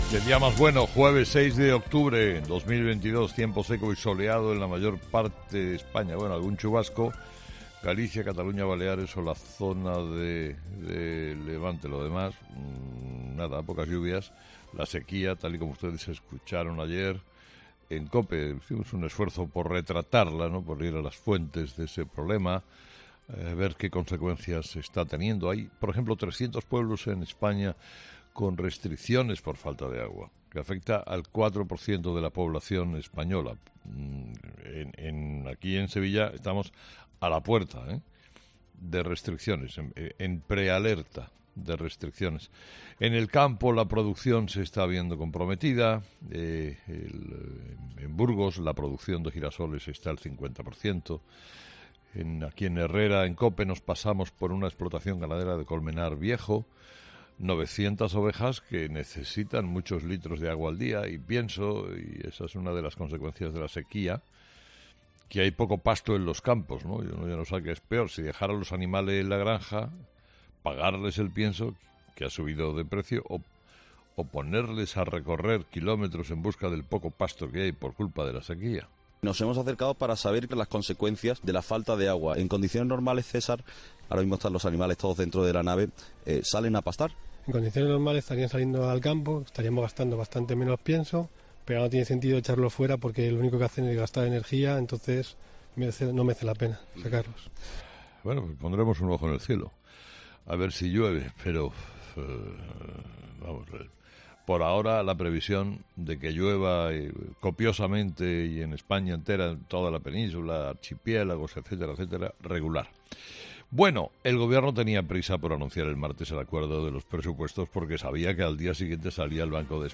Carlos Herrera repasa los principales titulares que marcarán la actualidad de este jueves 06 de octubre en nuestro país